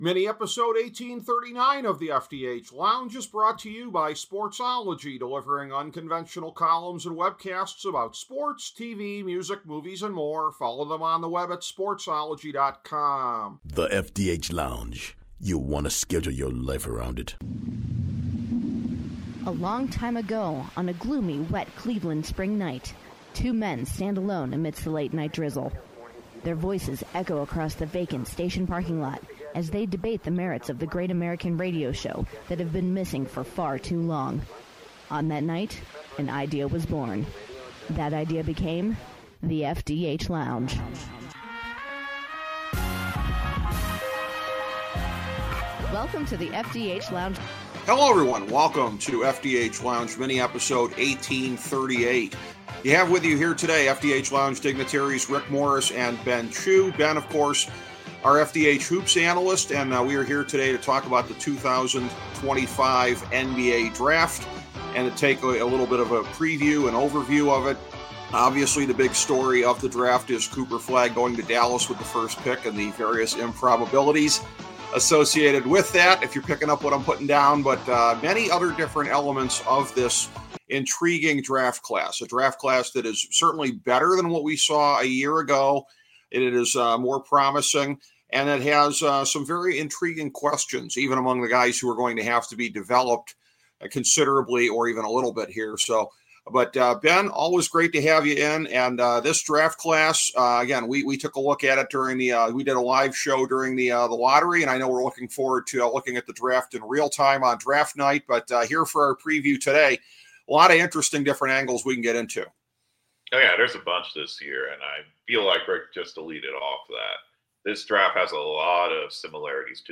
The FDH Lounge MINI-EPISODE #1830 — MAY 2025 — 2025 NBA DRAFT LOTTERY LIVE REACTION SHOW Play Episode Pause Episode Mute/Unmute Episode Rewind 10 Seconds 1x Fast Forward 30 seconds 00:00 / 53:04 Subscribe Share